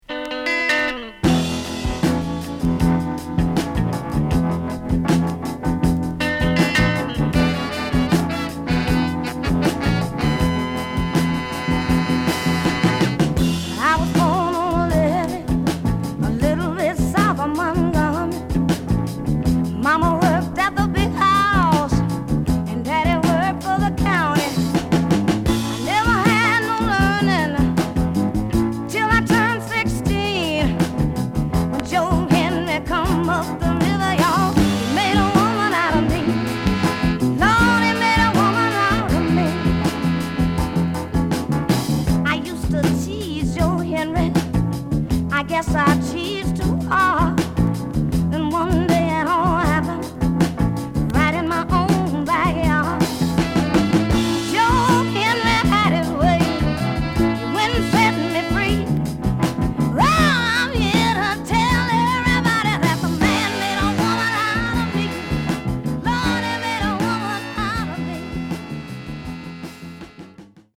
So sexy it hurts